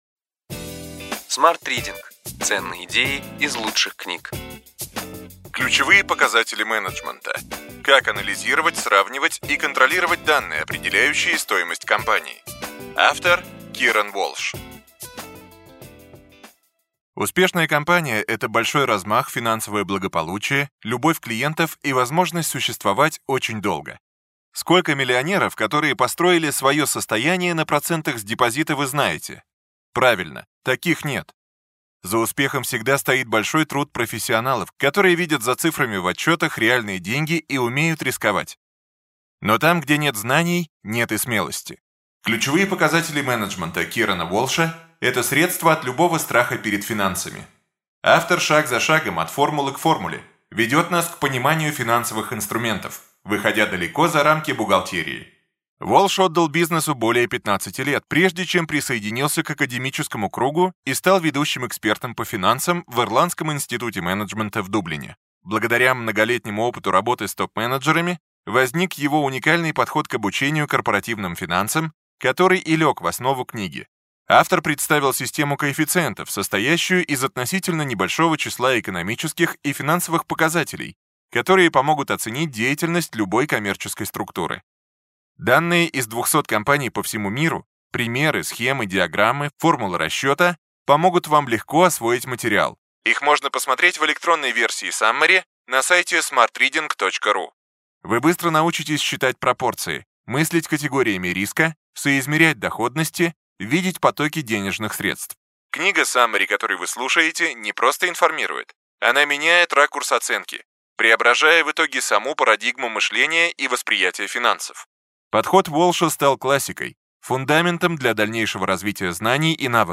Аудиокнига Ключевые идеи книги: Ключевые показатели менеджмента: как анализировать, сравнивать и контролировать данные, определяющие стоимость компании.